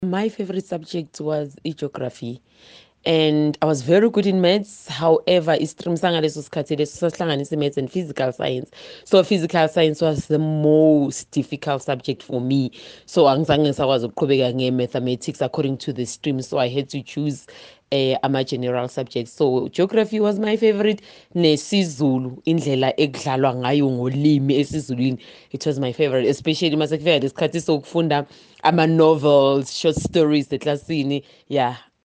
Here’s what Kaya Drive listeners had to say about the subjects and grades that showed them flames in school and their favourite subjects: